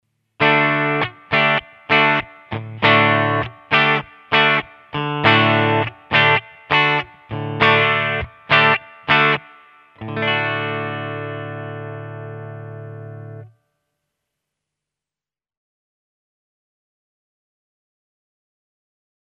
The singlecoil pickup
These slim and tall pickups tend to have a bright, cutting sound.
Telecaster clean
telecaster-clean.mp3